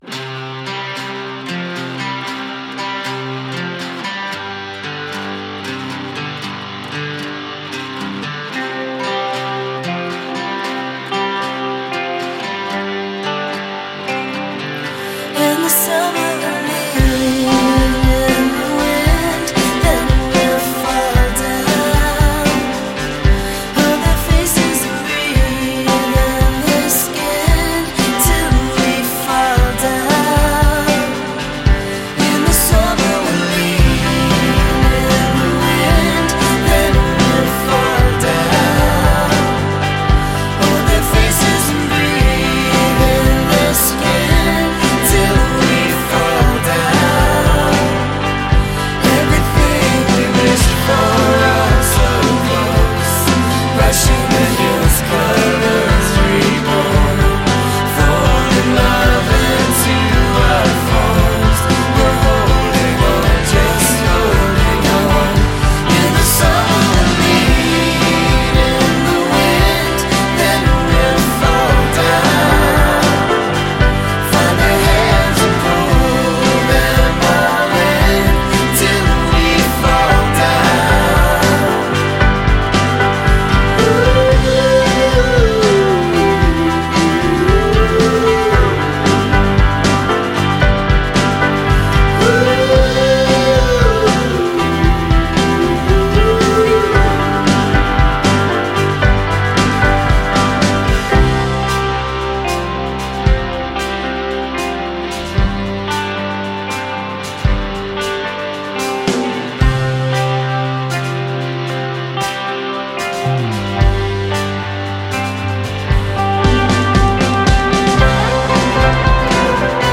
квинтет
элегантной камерной поп-музыки